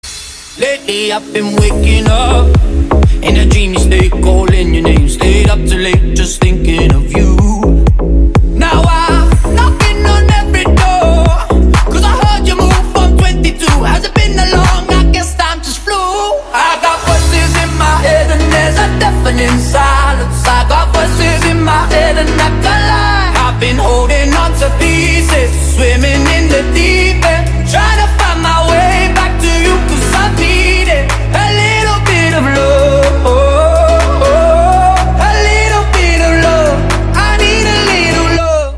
Dzwonki na telefon
Kategorie POP